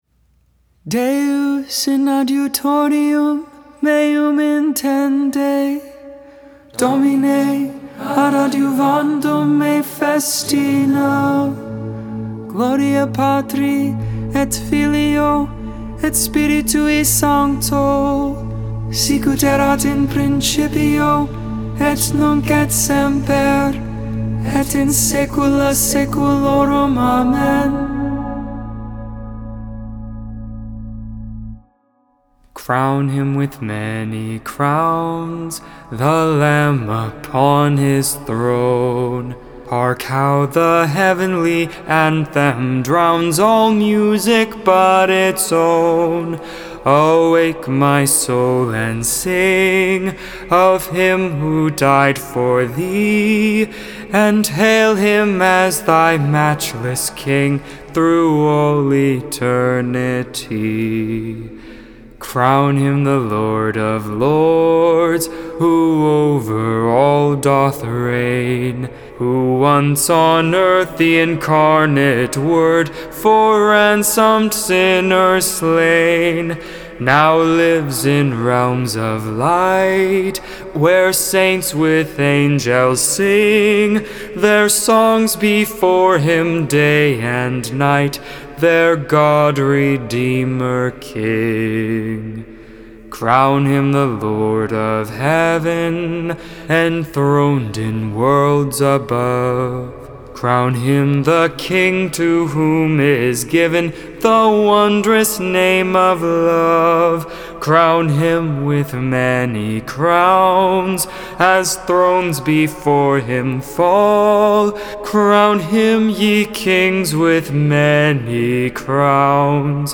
Hymn
tone 8